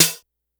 snr_10.wav